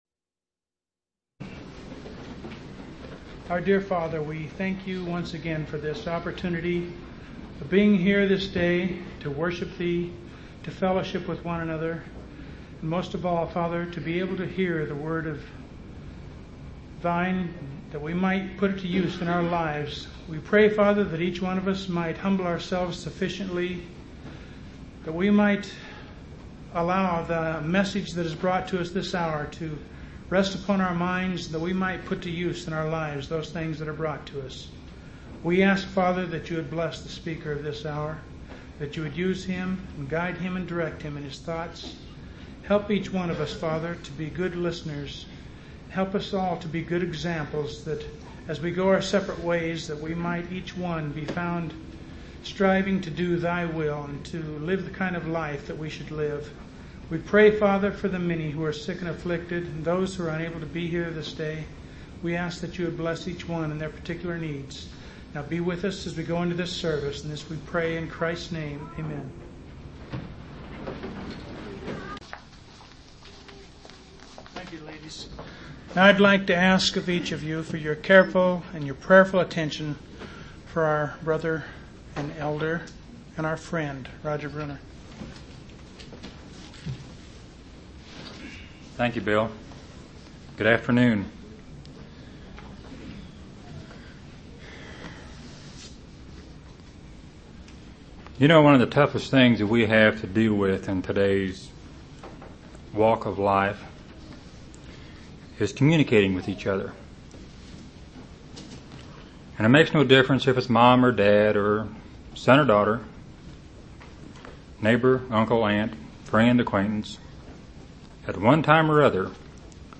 8/13/1988 Location: Missouri Reunion Event